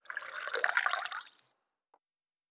Food Drink 04.wav